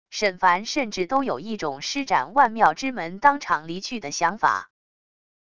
沈凡甚至都有一种施展万妙之门当场离去的想法wav音频生成系统WAV Audio Player